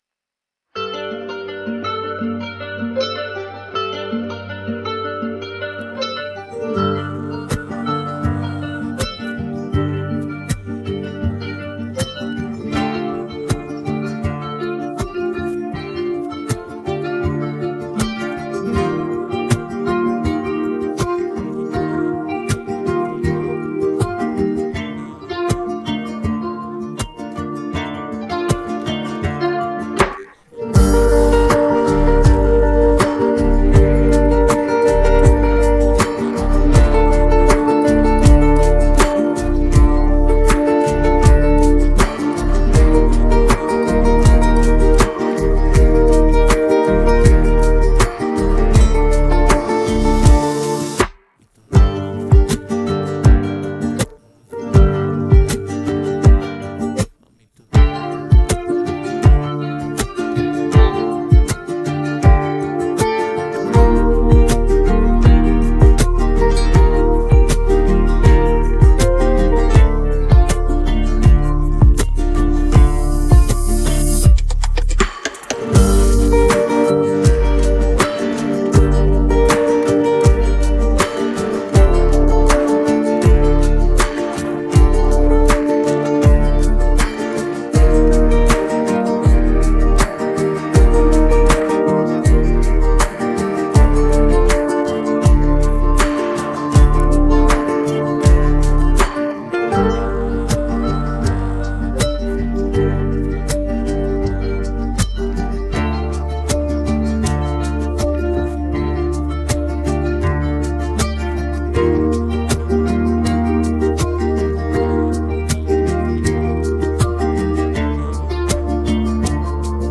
بیت بدون صدا خواننده
bi kalam